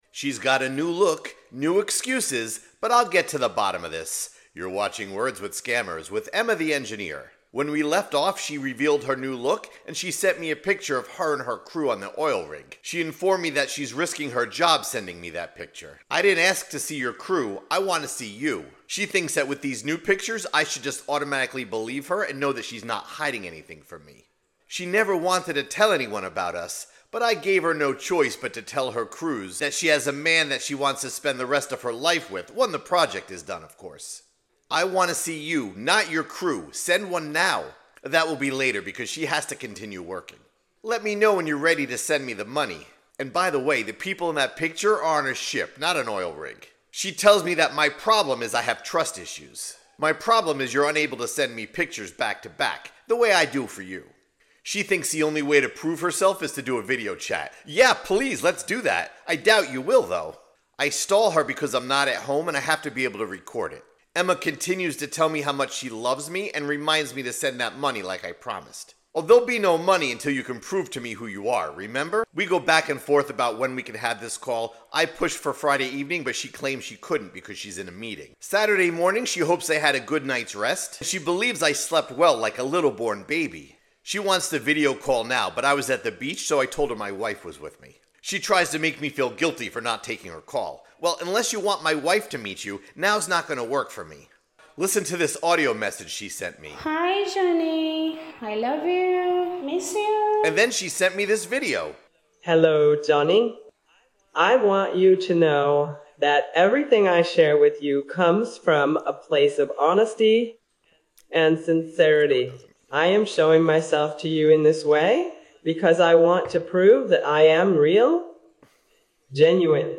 Listen to how the audio changes from a voice message to an AI video. Learn the red flags of a romance scammer.